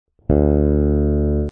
Cuerda segunda del bajo: RE (D)
La segunda cuerda está afinada en RE, o D en cifrado americano.
cuerda-re-al-aire.mp3